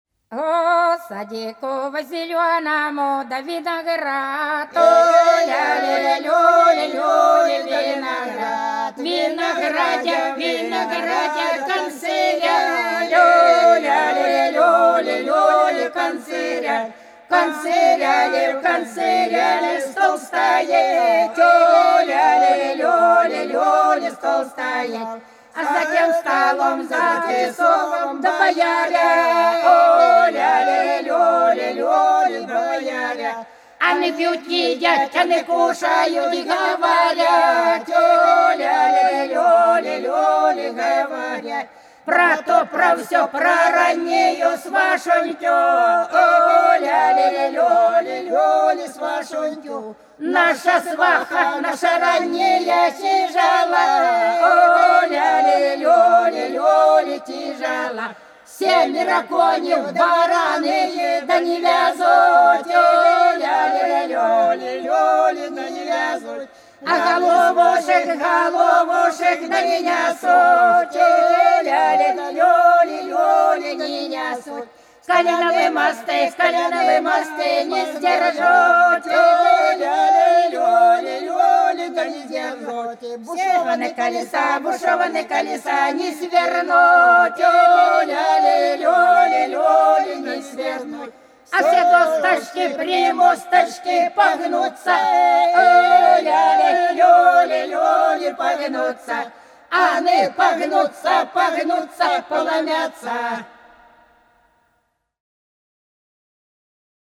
По-над садом, садом дорожка лежала А в садику да в зеленом виноград - свадебная (с.Плёхово, Курская область)
17_А_в_садику_да_в_зеленом_виноград_(свадебная).mp3